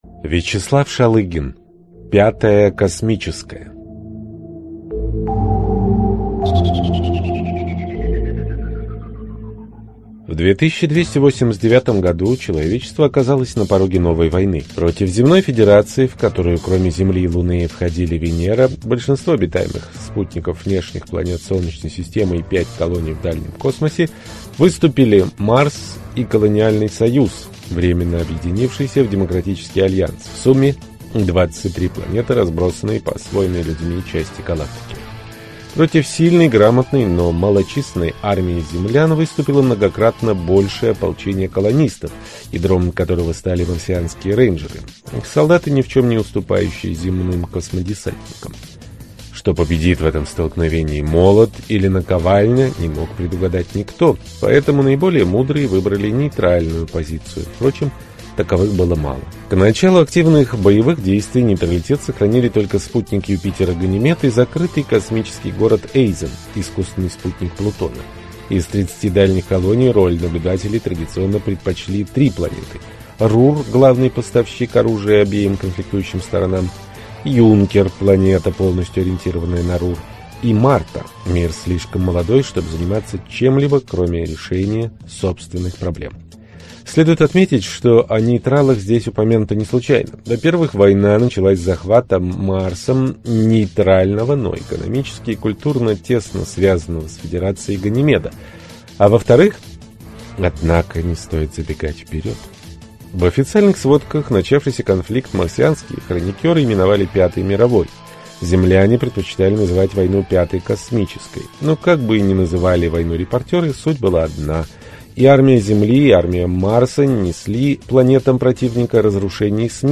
Аудиокнига Пятая Космическая | Библиотека аудиокниг